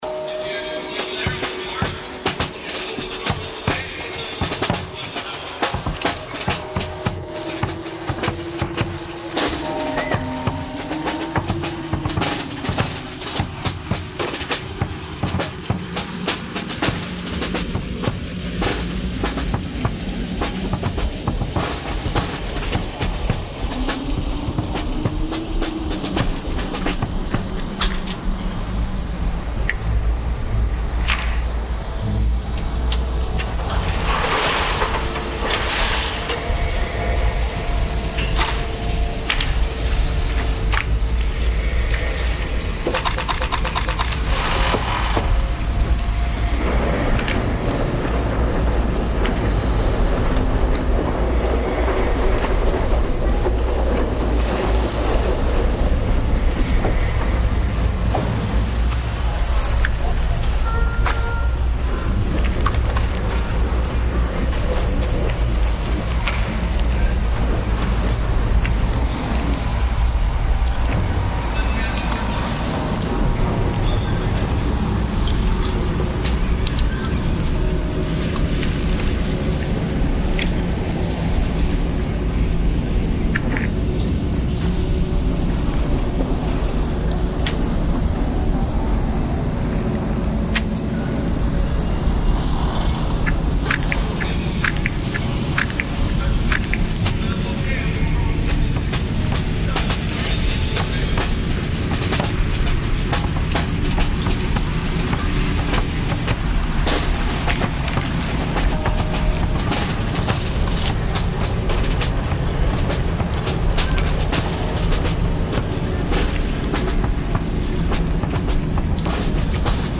I split the recording into four "stems" and then edited it down to 4 minutes, bounced them down then added some effects and some subtly detuning resonant bass."
Aker Brygge harbour soundscape